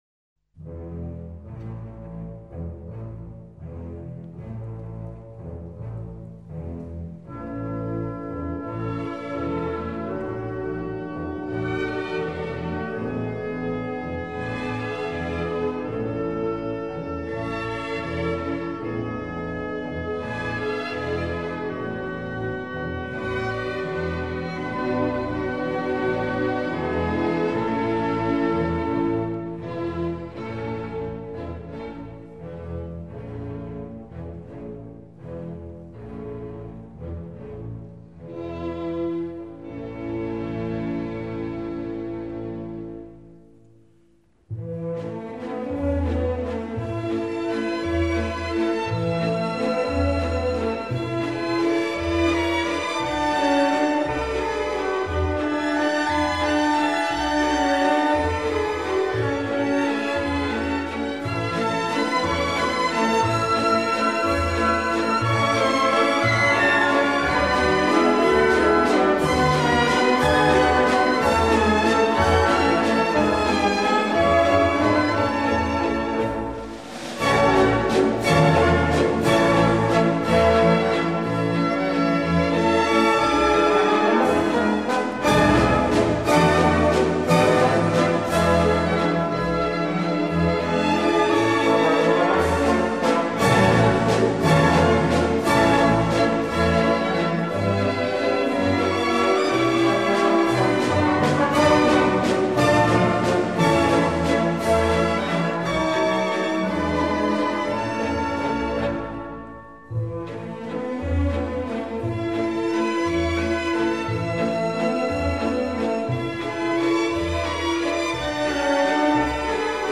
Tempo di valse
Et orkester
under ledelse af Robert Stolz spiller